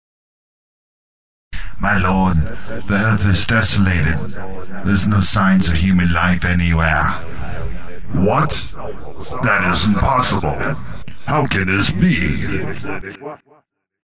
电影影视氛围型音效
标签： 电影 氛围 开场
声道立体声